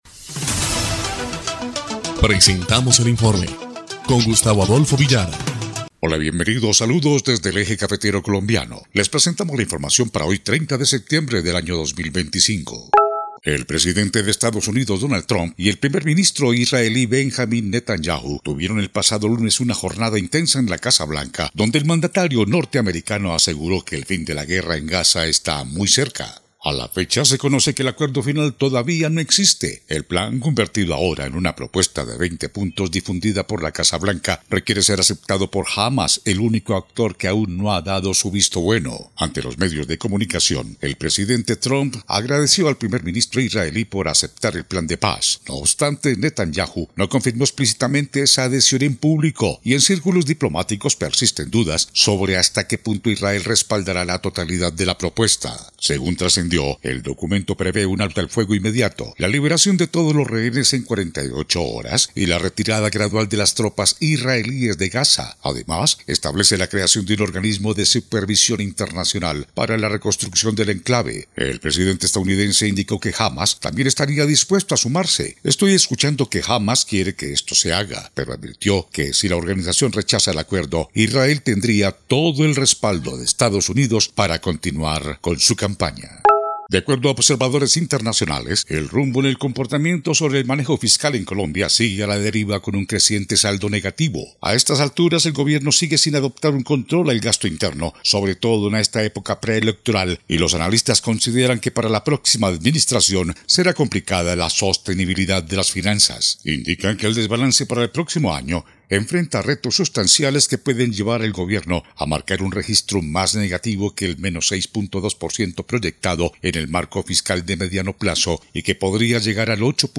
EL INFORME 2° Clip de Noticias del 30 de septiembre de 2025